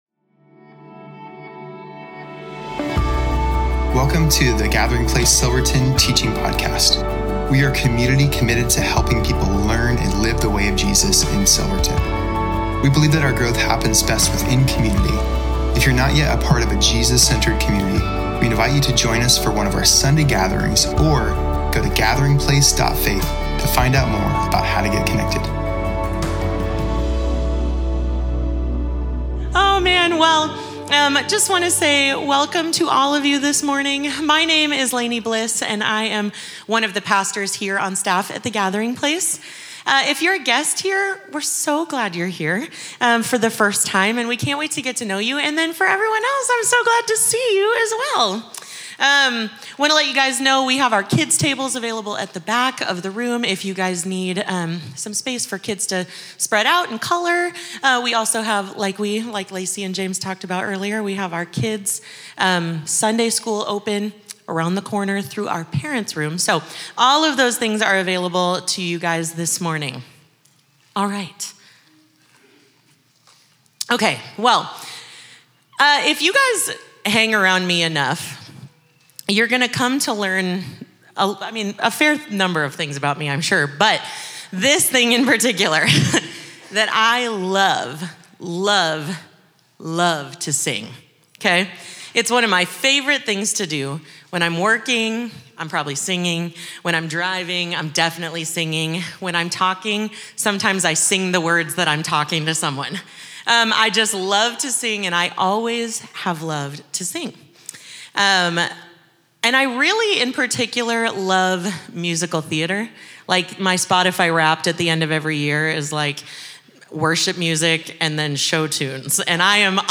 Home About Connect Events Sermons Give The Fruit of The Spirit-Part 4-Patience October 26, 2025